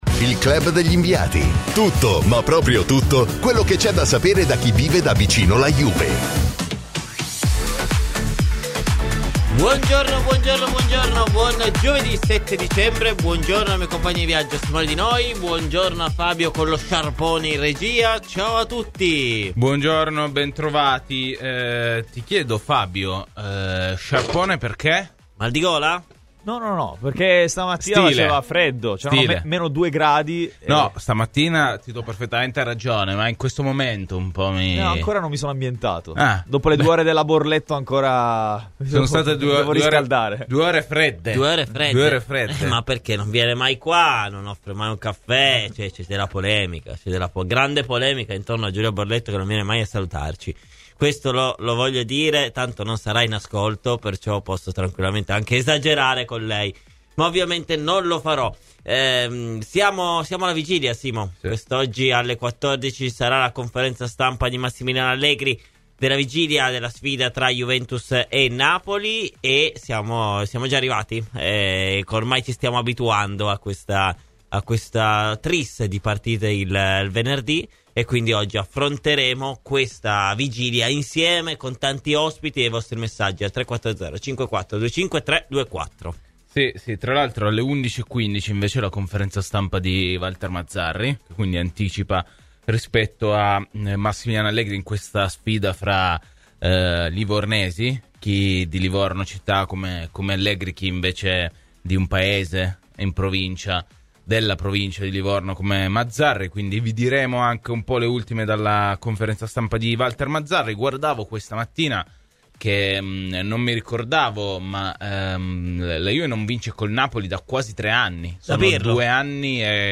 intervenuto ai microfoni di Radio Bianconera nel corso de Il Club degli inviati